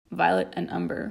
A segment of the "Spoken Text" audio file, specifically the phrase "violet and umber". No further effects were added. This sound is correlated to the letter "z" on the computer keygboard.